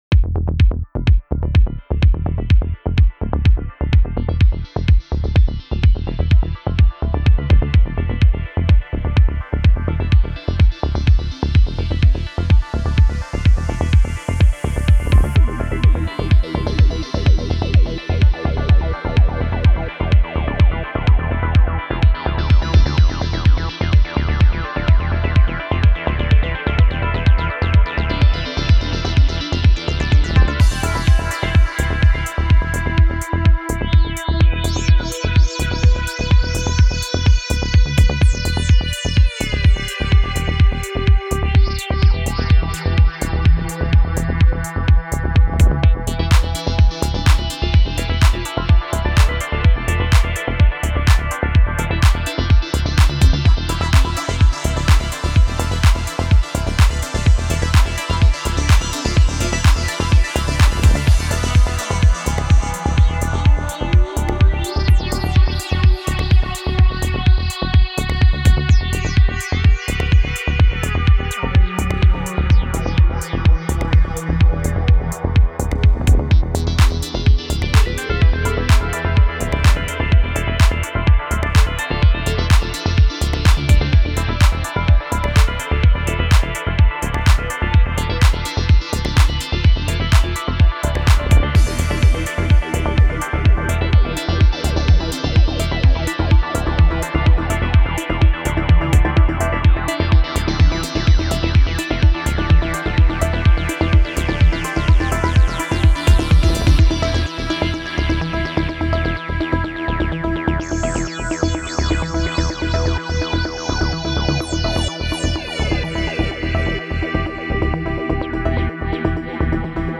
Genre Trance